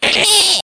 Monty Mole sound effect from Mario Kart Wii
Monty_Mole_SFX_-_Mario_Kart_Wii.mp3